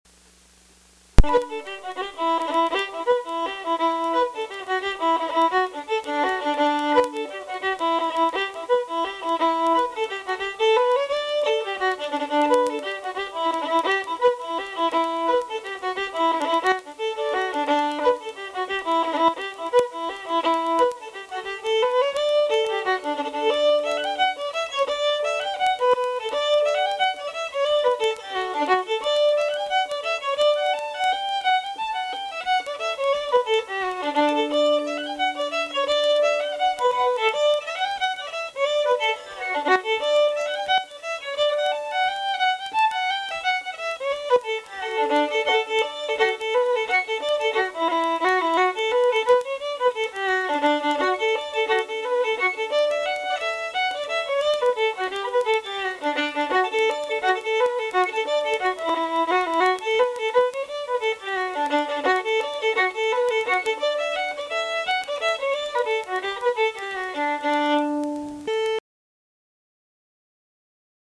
Session Tunes